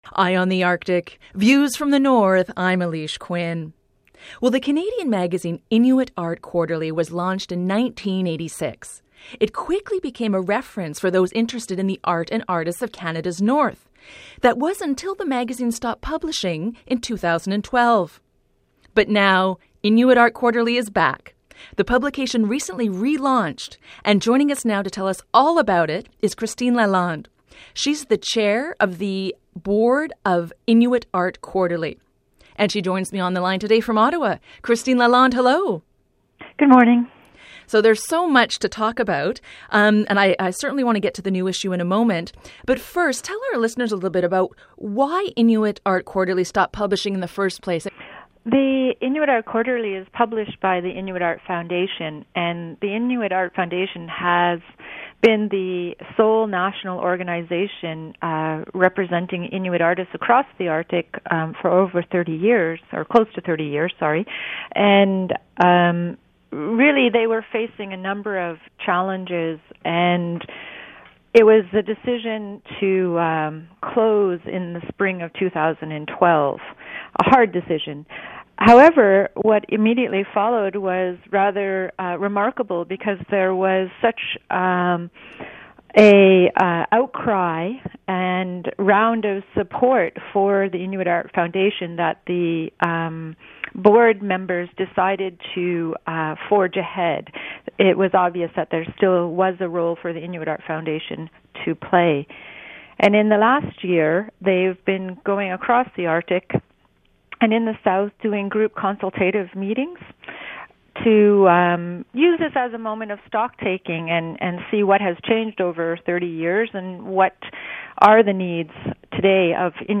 Feature Interview: The return of Inuit Art Quarterly (Audio)